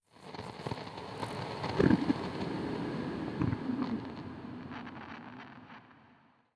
Minecraft Version Minecraft Version latest Latest Release | Latest Snapshot latest / assets / minecraft / sounds / ambient / nether / warped_forest / addition5.ogg Compare With Compare With Latest Release | Latest Snapshot